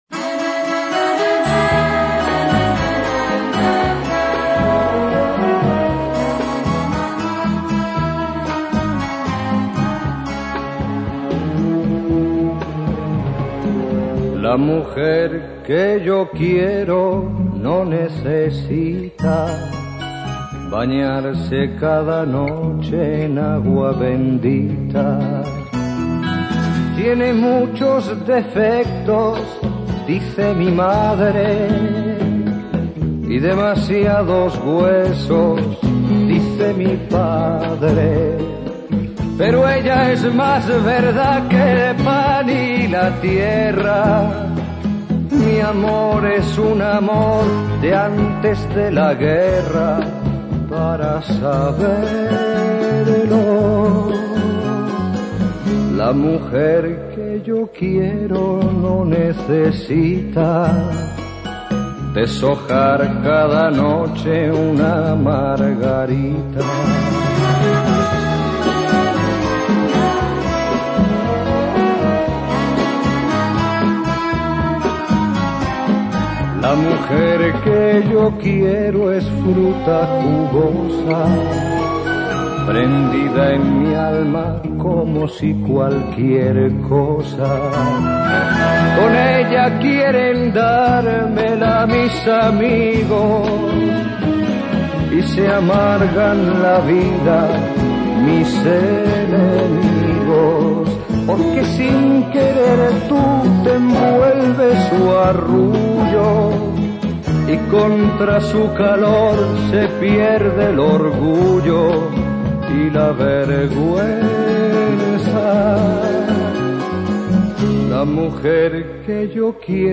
Trova.